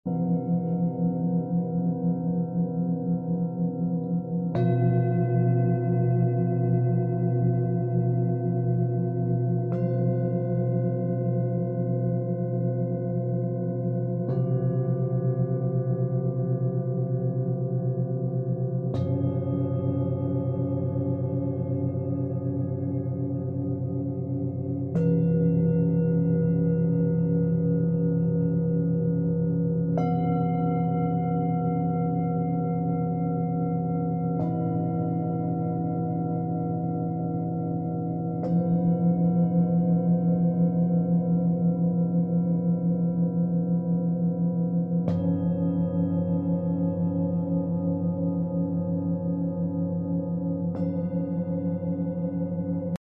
Sound Bath Recording